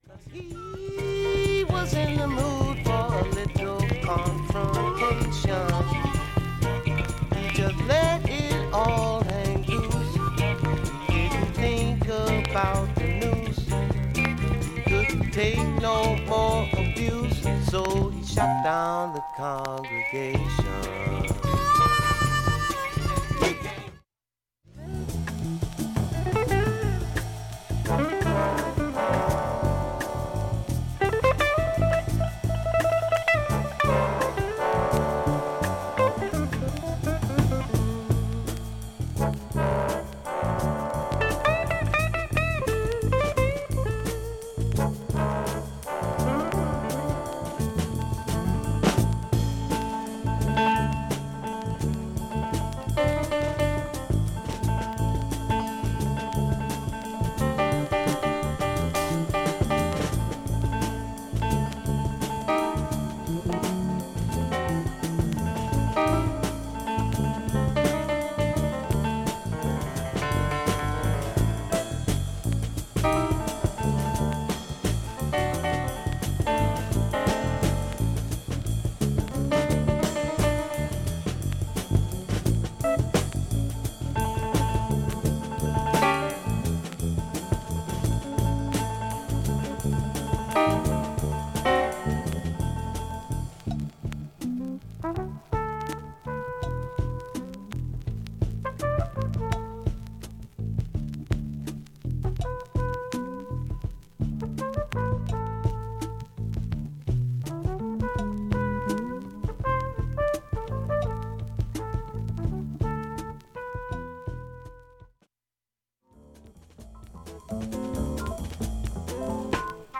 薄いスレ程度で普通に聴けます全曲試聴済み。
A-1序盤に９回のかすかなプツ出ます。
周回プツ出ますがかすかなレベルです。